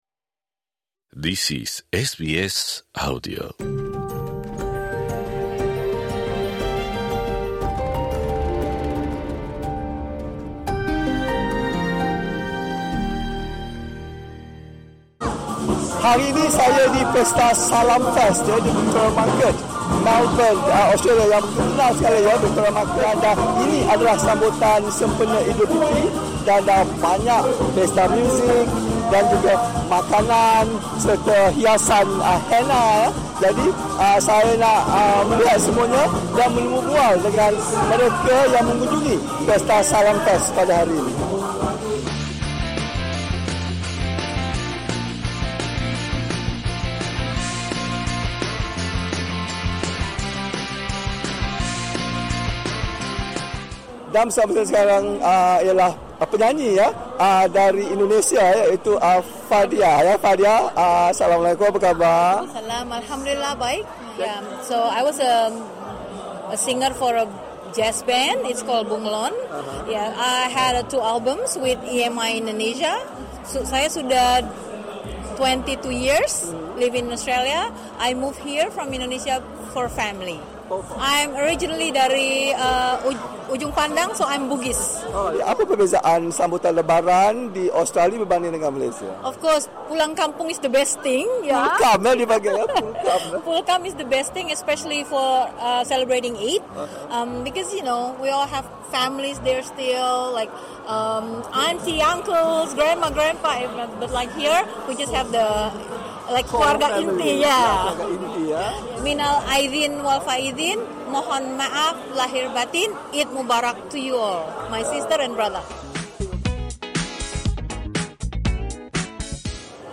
Sempena penghujung bulan Syawal yang mulia tahun 1445 Hijriah ini, SBS Bahasa Melayu paparkan sambutan Salam Fest menyambut Eidul Fitri di pasar yang menarik pelawat dari seluruh dunia, the Queen Victoria Market. Ikuti tinjauan SBS Melayu beramah mesra dengan pengunjung menyambut Eidul Fitri di kota Melbourne yang berbilang kaum.